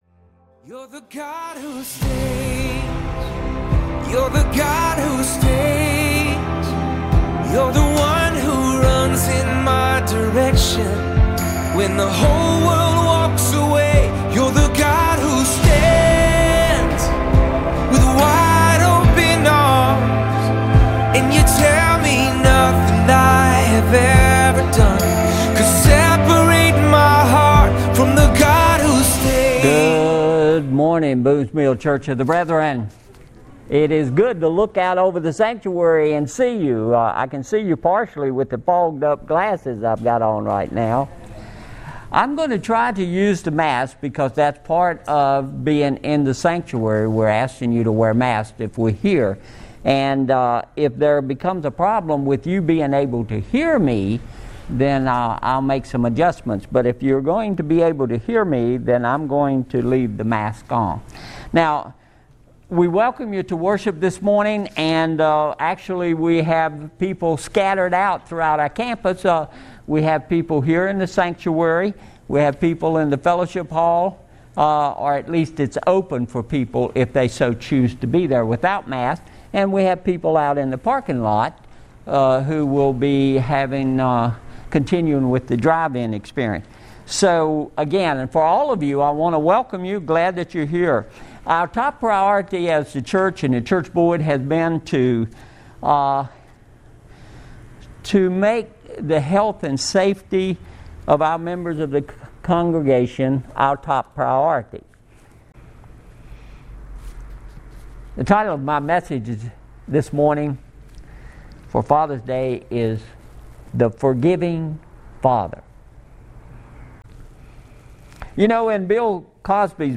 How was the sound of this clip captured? Father's Day Celebration